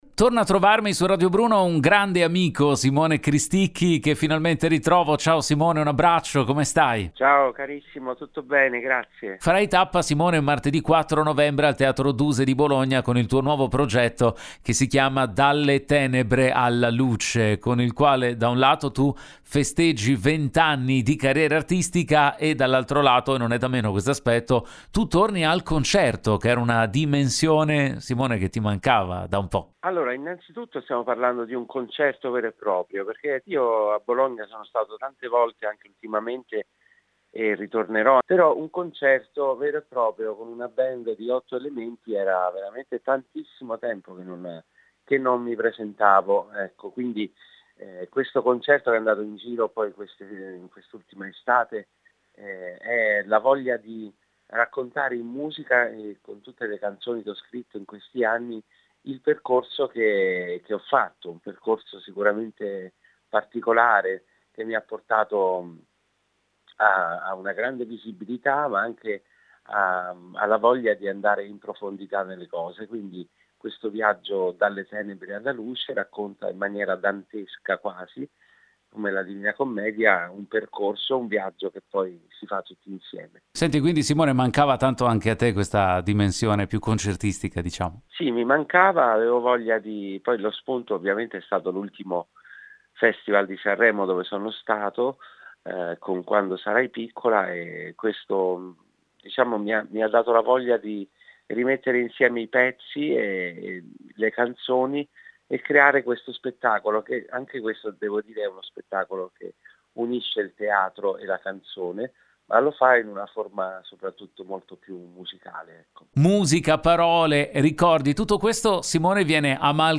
Home Magazine Interviste Simone Cristicchi presenta “Dalle tenebre alla luce”
al telefono